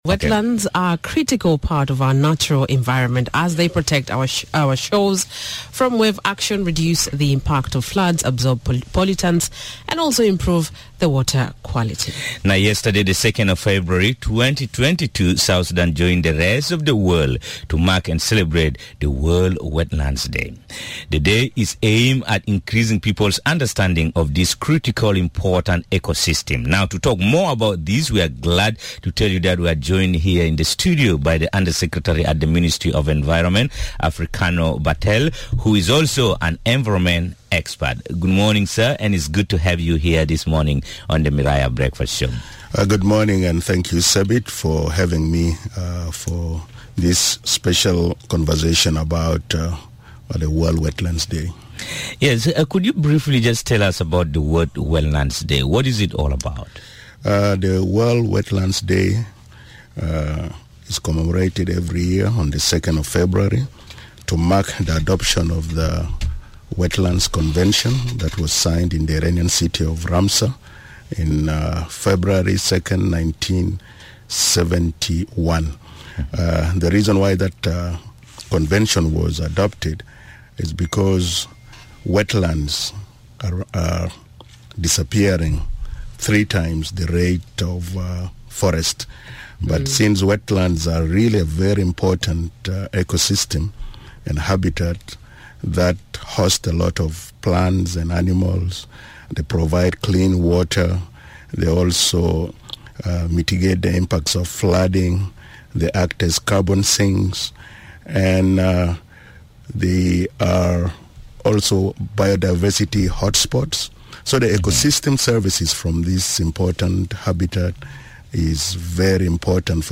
Undersecretary at the Ministry of Environment Africano Barthel, says the environmental sector needs a lot of investment to protect the natural resources. Barthel says government has introduced several policies but is faced with the challenge of implementation due to lack of resources. He was speaking during the Miraya breakfast show this morning. Take a listen to the full interview.